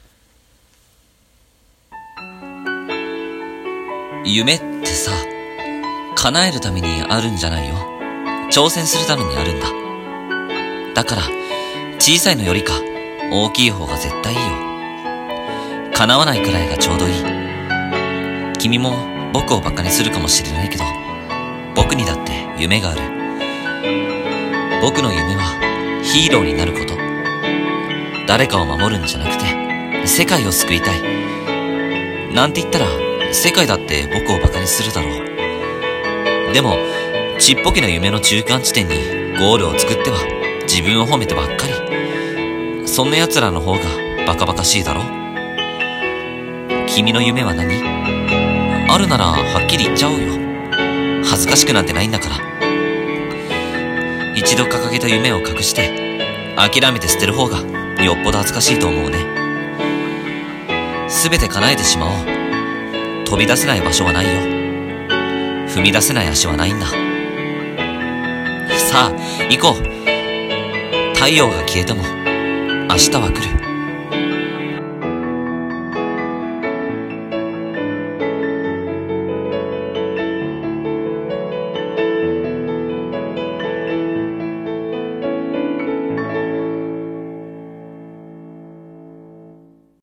声劇】 夢と僕